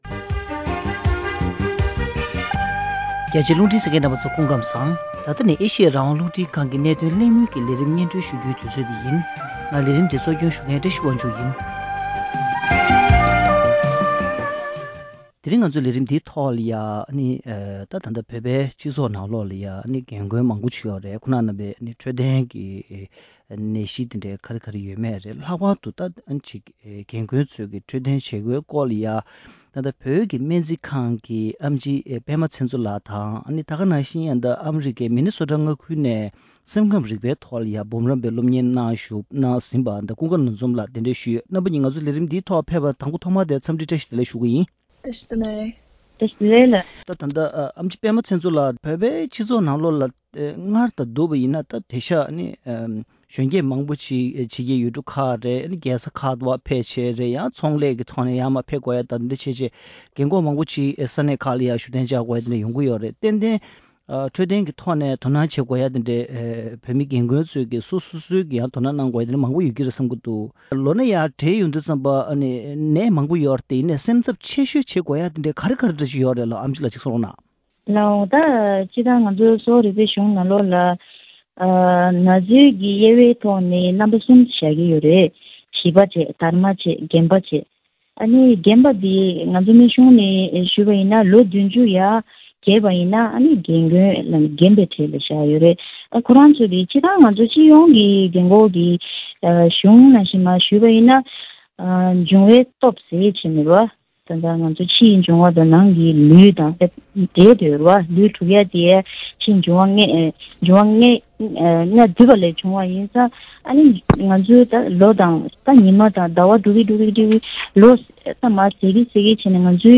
བོད་མིའི་སྤྱི་ཚོགས་ཁྲོད་རྒན་རྒོན་མང་པོ་ཡོད་པ་རྣམས་ཀྱི་ལུས་སེམས་གཉིས་ཀྱི་འཕྲོད་བསྟེན་ལྟ་སྐྱོང་བྱེད་ཕྱོགས་དང་སྔོན་འགོག་འཕྲོད་བསྟེན་བཅས་ཀྱི་ཐད་གླེང་མོལ།